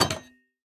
Minecraft Version Minecraft Version latest Latest Release | Latest Snapshot latest / assets / minecraft / sounds / block / copper_door / toggle1.ogg Compare With Compare With Latest Release | Latest Snapshot